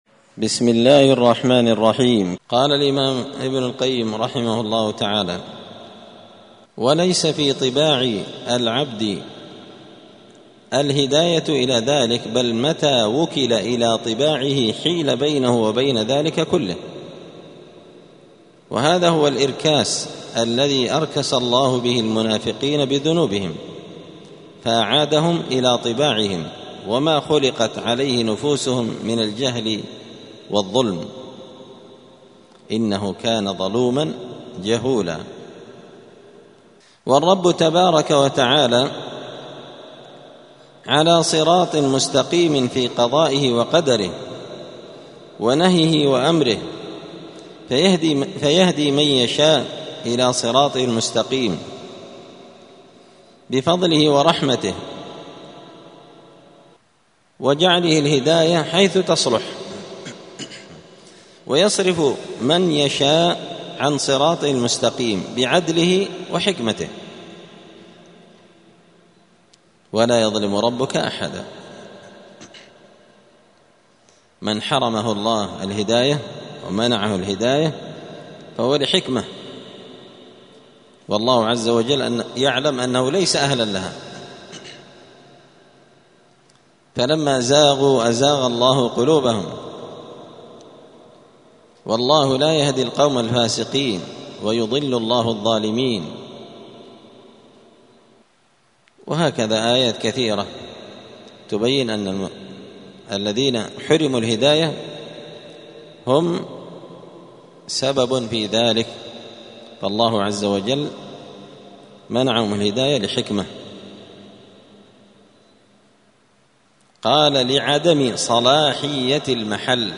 *الدرس السادس والخمسون (56) فصل أصل الذنوب*
دار الحديث السلفية بمسجد الفرقان بقشن المهرة اليمن